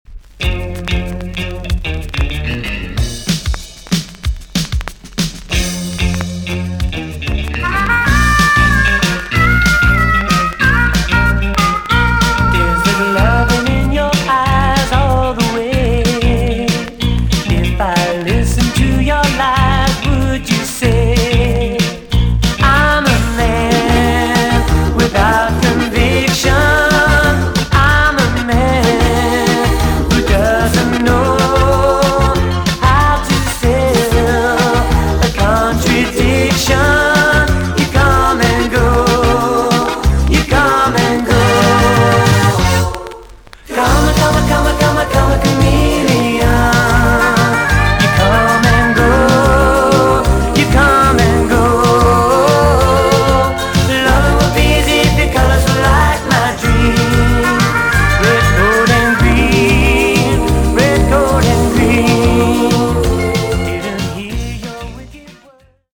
VG+ 少し軽いチリノイズがあります。